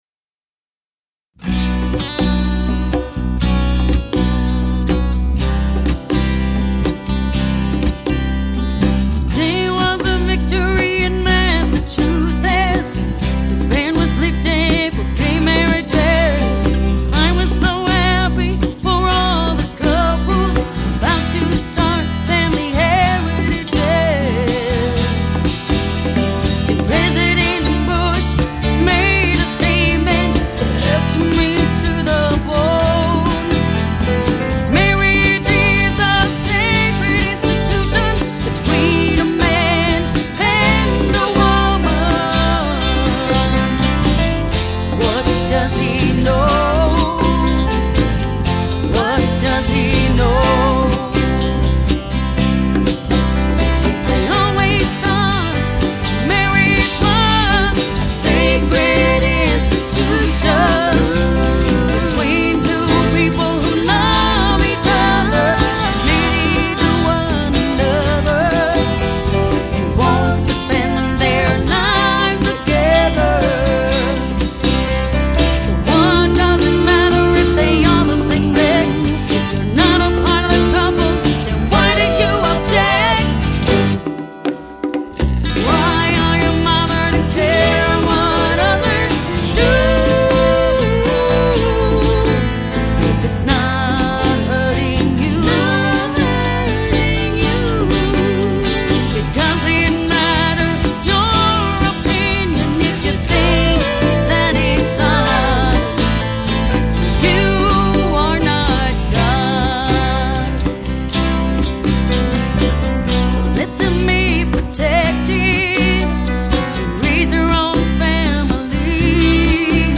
(West Coast Swing, 3:46, 2004)